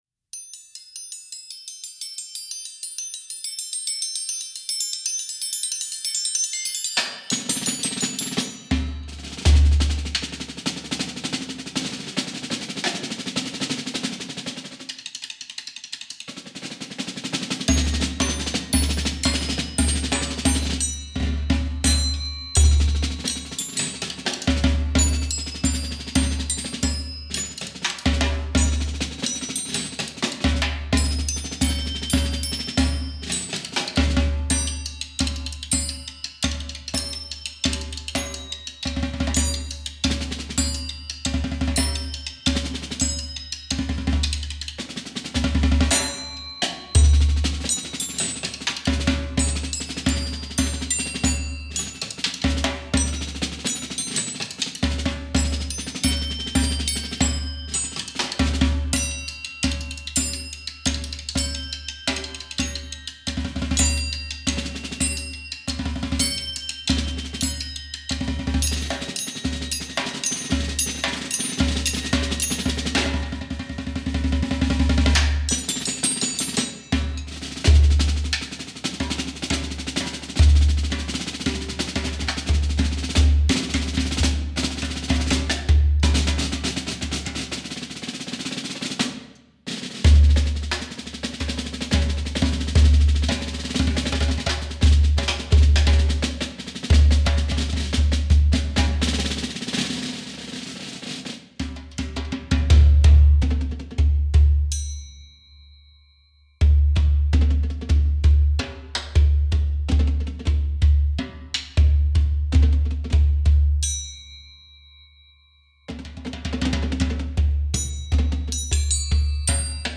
Voicing: Percussion Duet